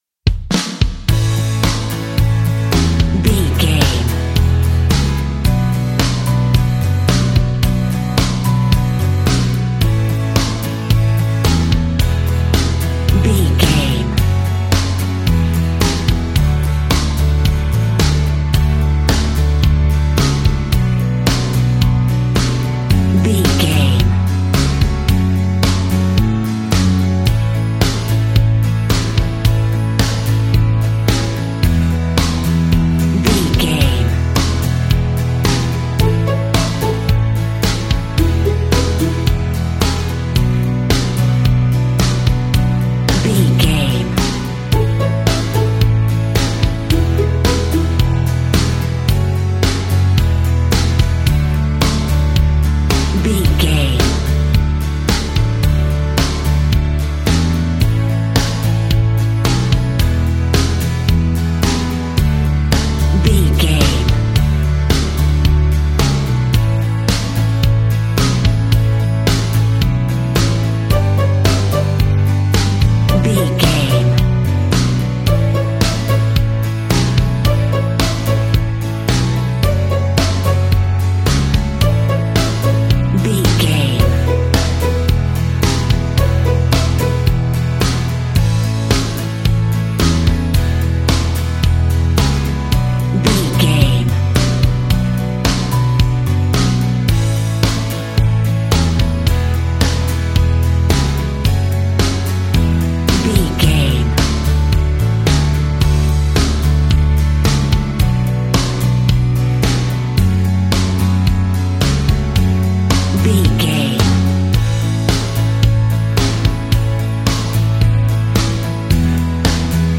Ionian/Major
calm
melancholic
smooth
soft
uplifting
electric guitar
bass guitar
drums
strings
pop rock
indie pop
organ